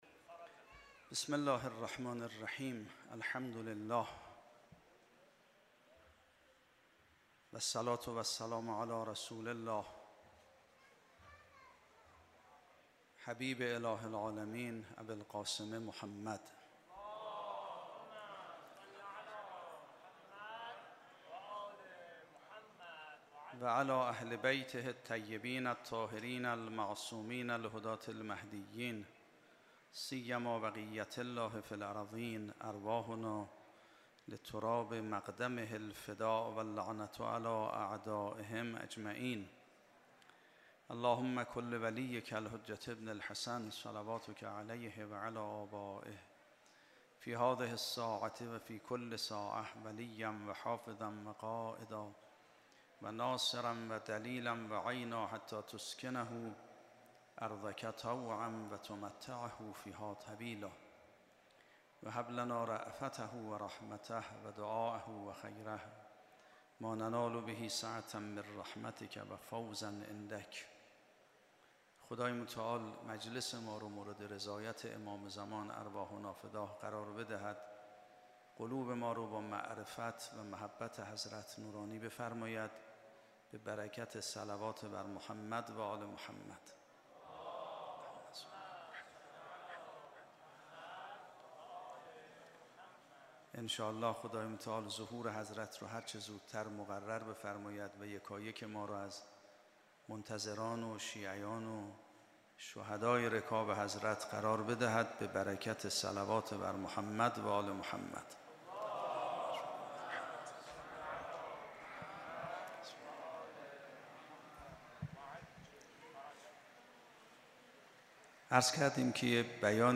سخنرانی استاد میرباقری با موضوع "امامان هدایت و ضلالت" در شب دوم محرم الحرام 14‌01